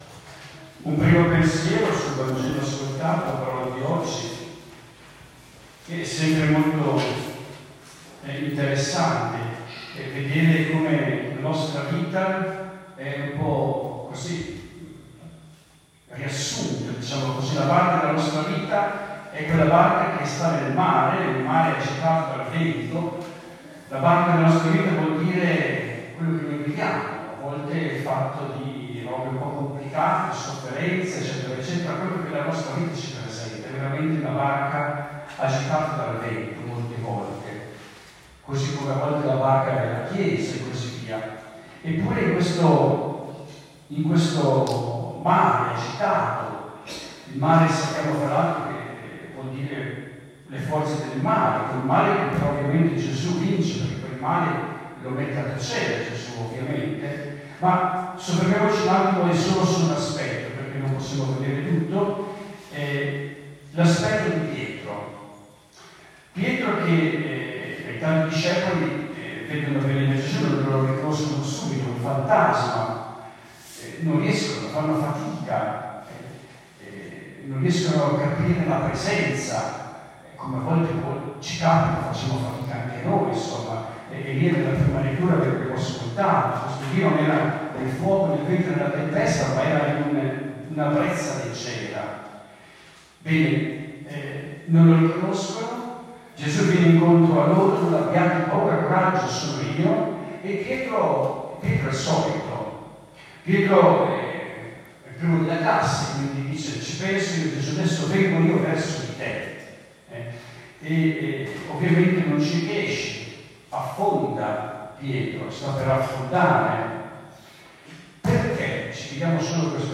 Ecco la registrazione audio dell'omelia